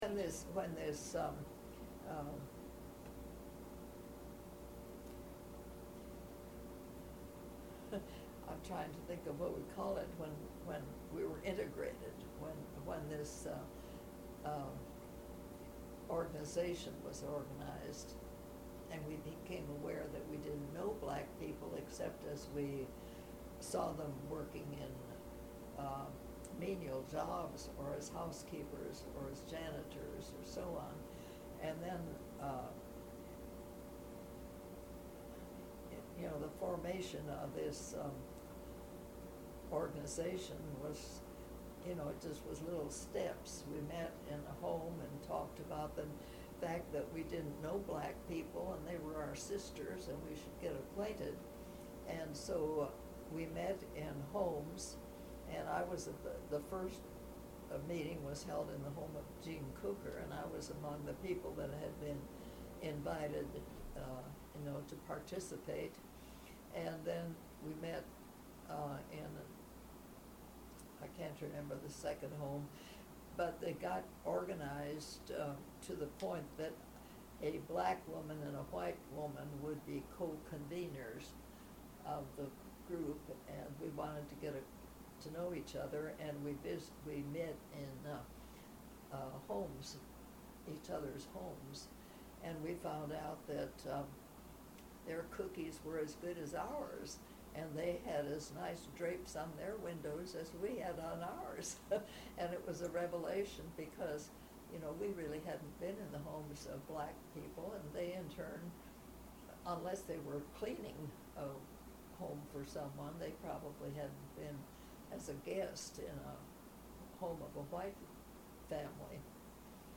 UNT Oral History Program